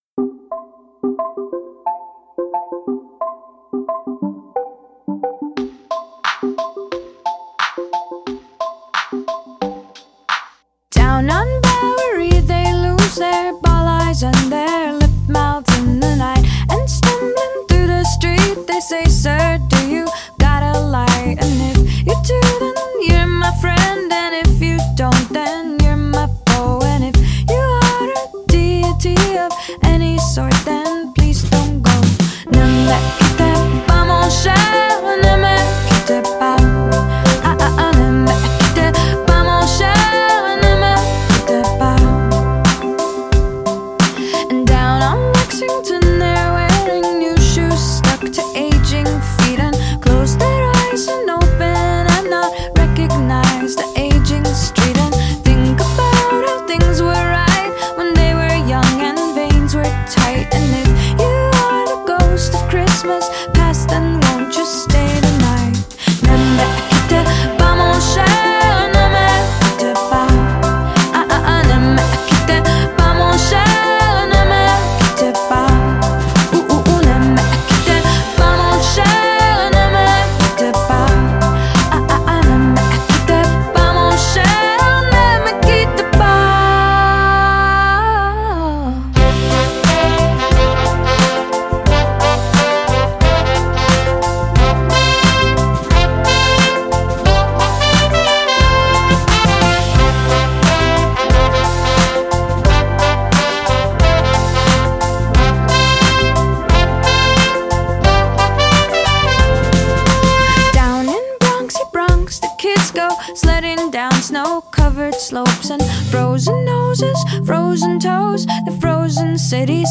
live piano